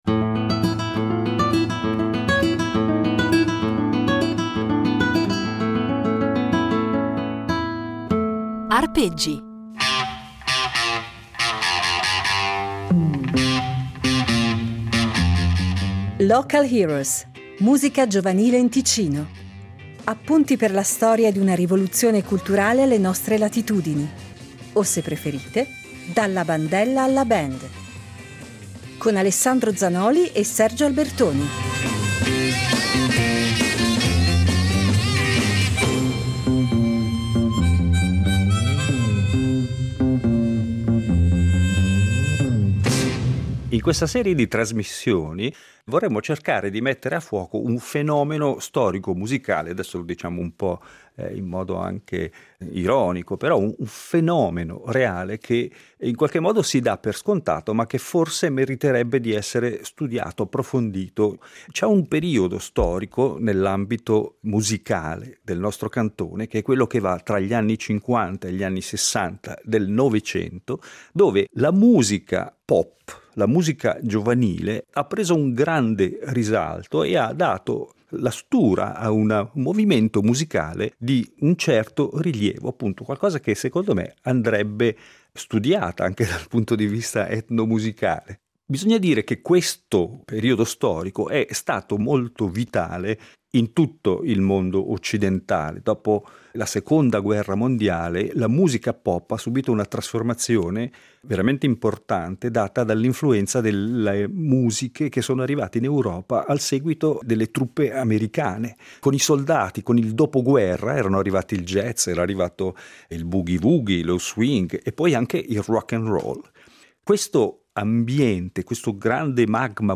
In ognuna delle puntate di “Local Heroes” affronteremo un tema diverso, e daremo la parola a un ospite diverso, ma ascolteremo anche molta musica. Musica piena di energia ed entusiasmo, che vale sicuramente la pena di far risuonare, a distanza di decenni.